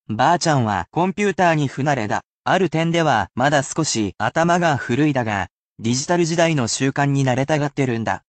(casual speech)